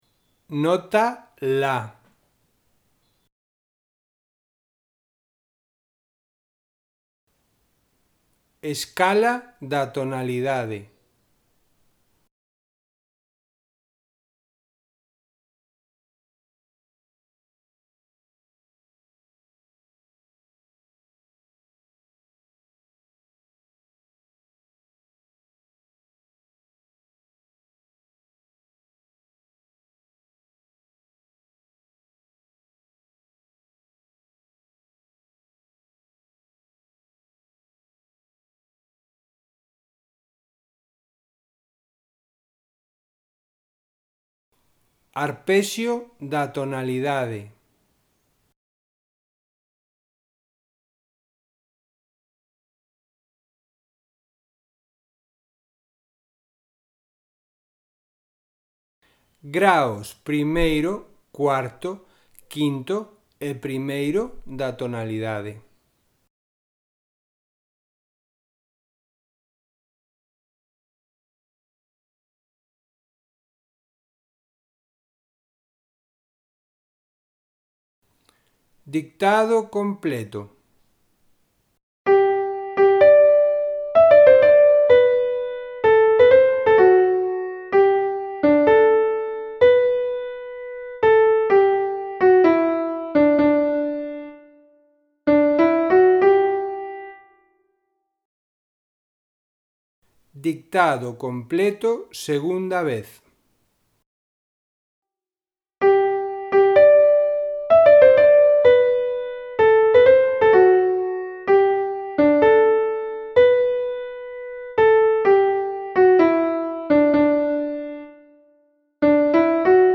Ditados a 1 voz
Cada un dos audios é un ditado completo, con escala, acordes e arpexios e a secuencia de repetición.